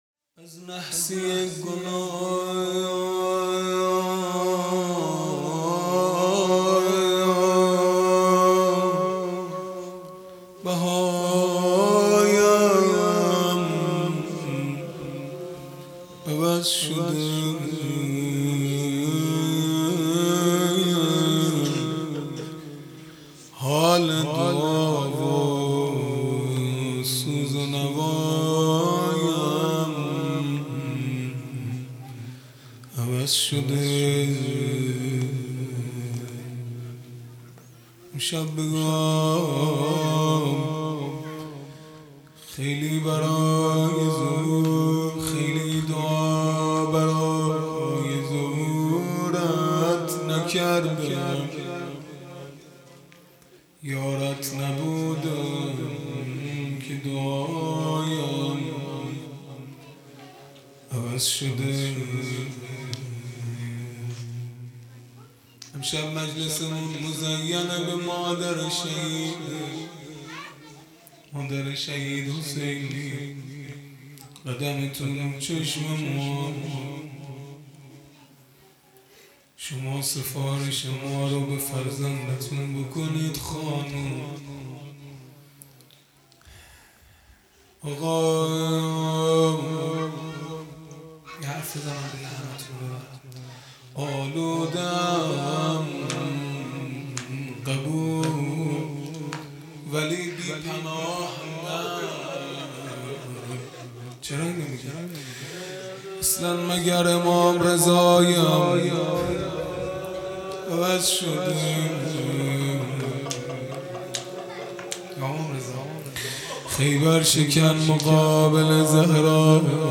0 0 مناجات پایانی | از نحسی گناه بهایم عوض شده
فاطمیه(شب اول)